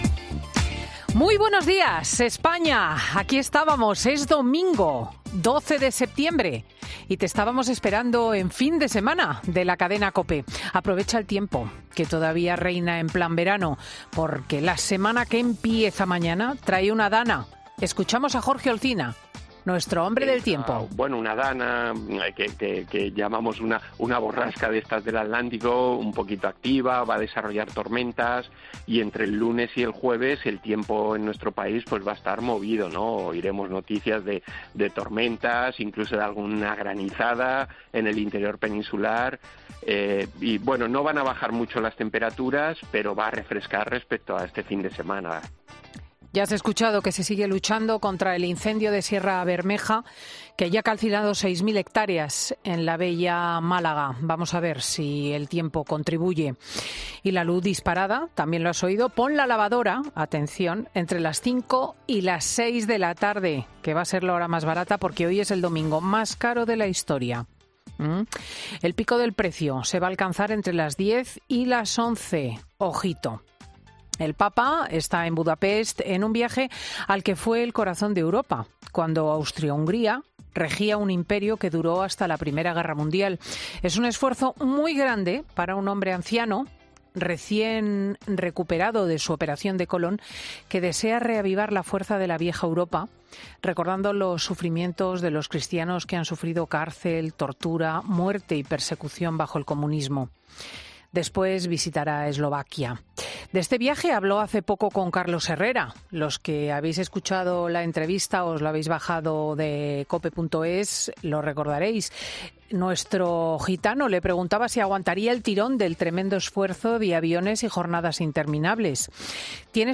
Monólogo de Cristina López Schlichting